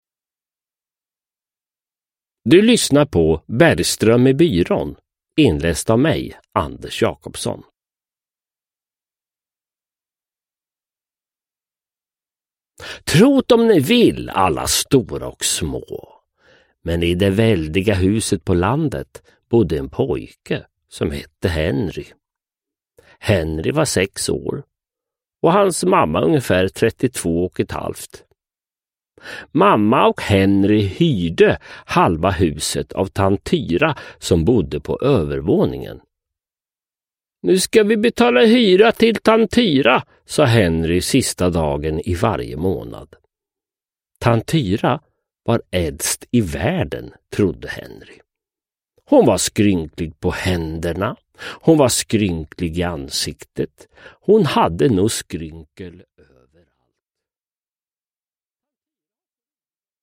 Uppläsare: Sören Olsson, Anders Jacobsson